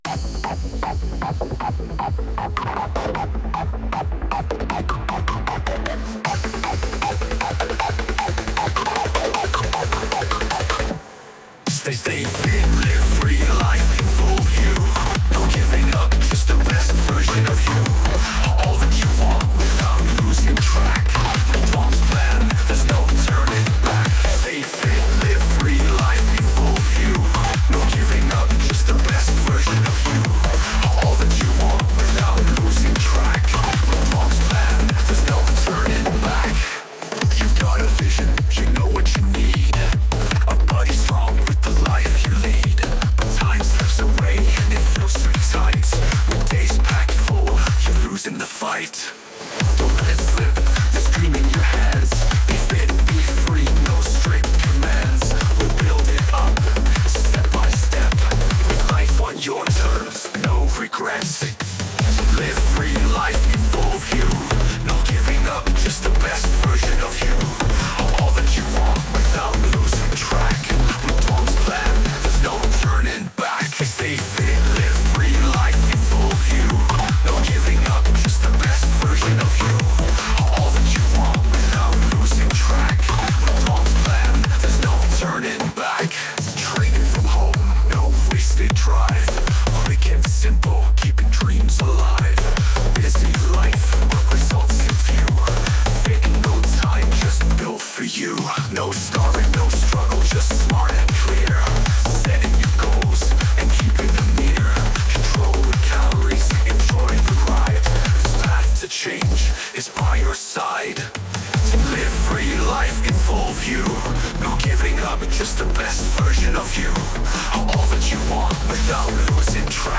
Kostenlose Lieder mit motivierenden Texten (und geilem Beat ;‒)